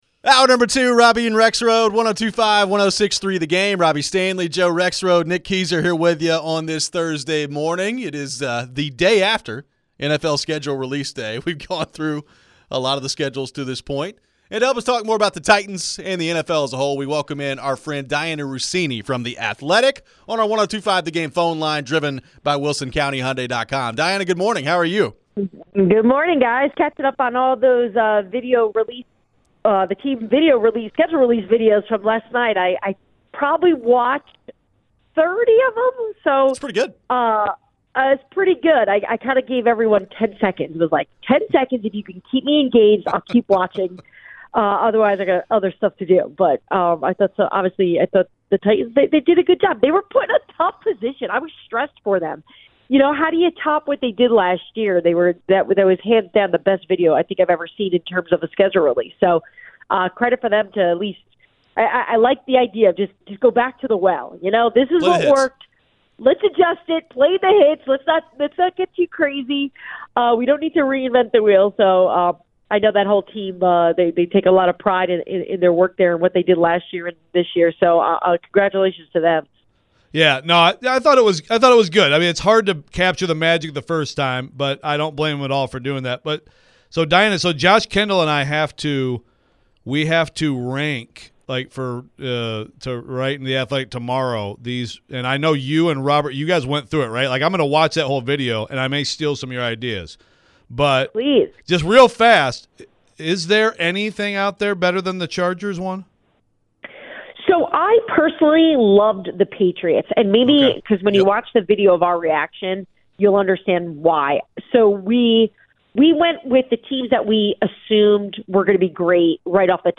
Dianna Russini Interview (5-16-24)